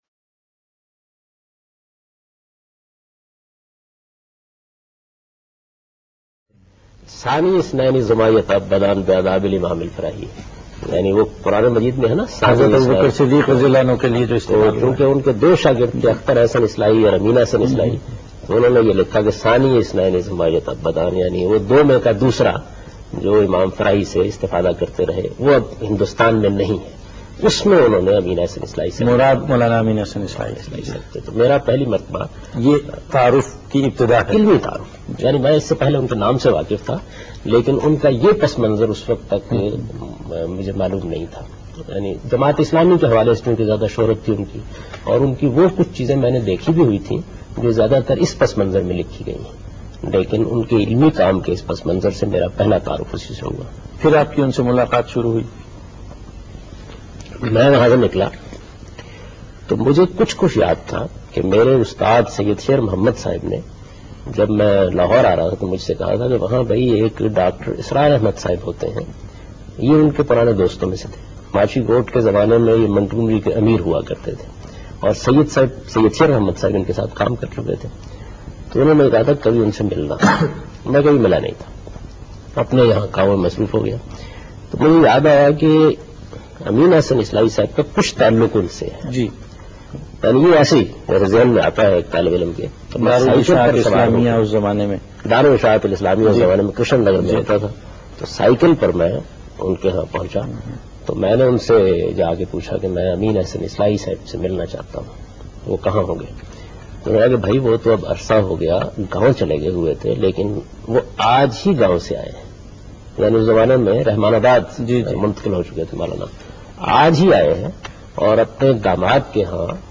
Javed Ahmad Ghamidi > Videos > [2/2] Personal Interview of Javed Ahmed Ghamidi on Spring Festival